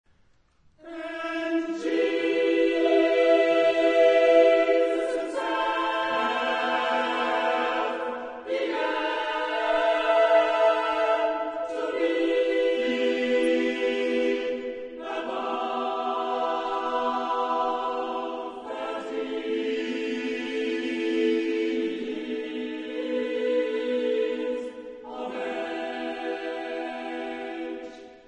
Genre-Style-Form: Sacred ; Motet
Type of Choir: SSAATTTBBB  (10 mixed voices )
Tonality: free tonality ; modal